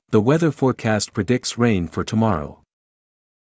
/tts/examples_azure/t/